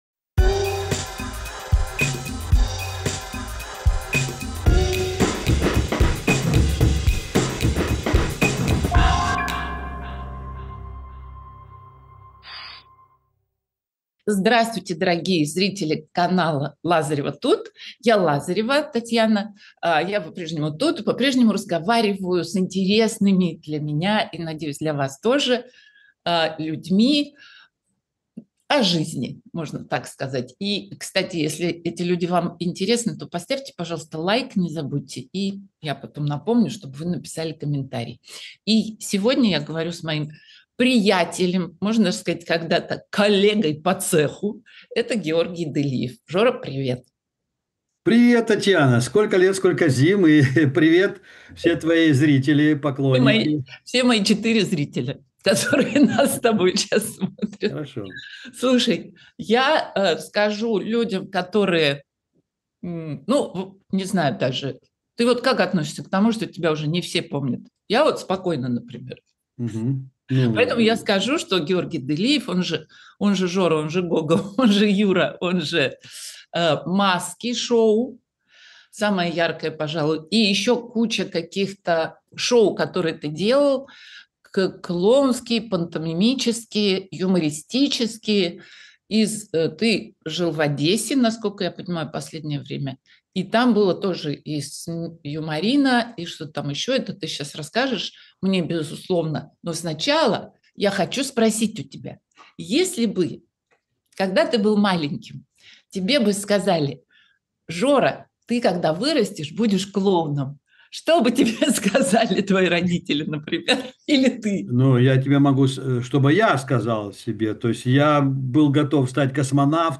Эфир ведёт Татьяна Лазарева